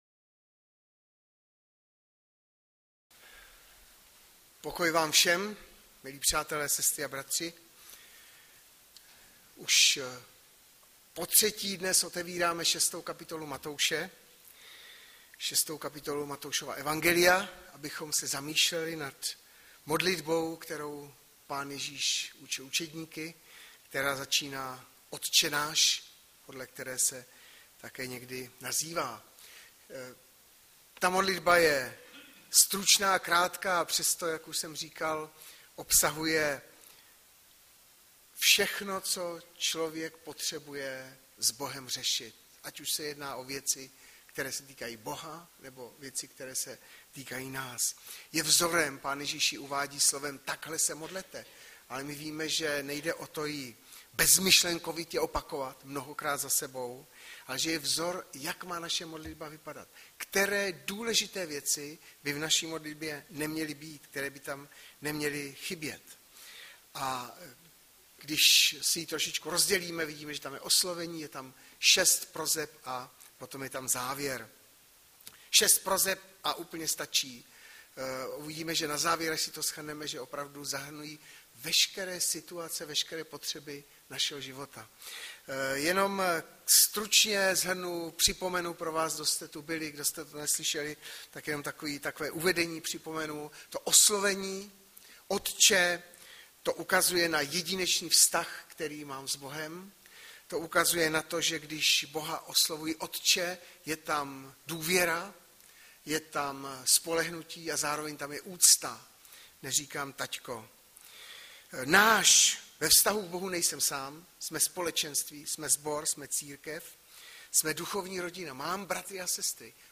Hlavní nabídka Kázání Chvály Kalendář Knihovna Kontakt Pro přihlášené O nás Partneři Zpravodaj Přihlásit se Zavřít Jméno Heslo Pamatuj si mě  21.05.2017 - MODLITBA PÁNĚ III. Naše potřeby